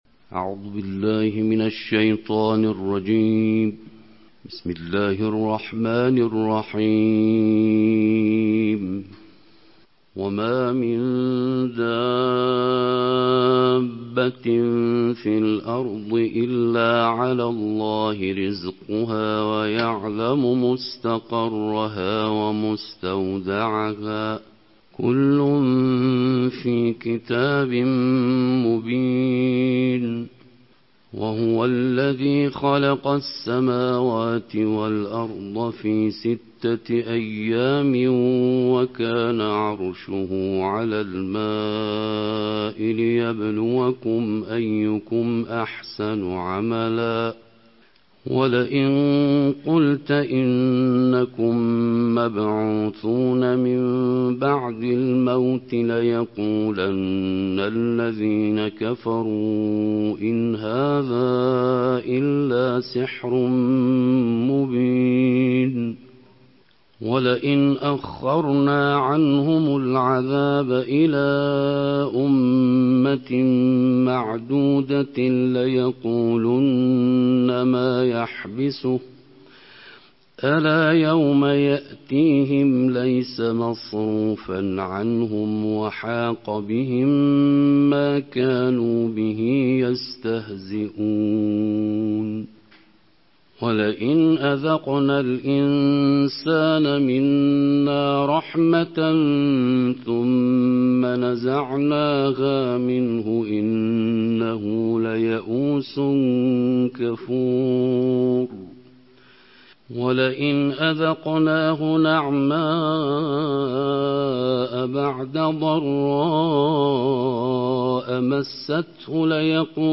Чтение 12-го джуза Корана голосом международных чтецов + аудио